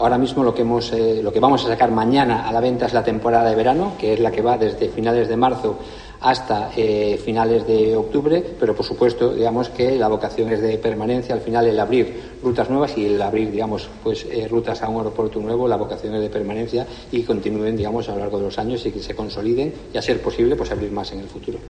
Así lo ha informado la aerolínea, en una rueda de prensa en el Ayuntamiento de A Coruña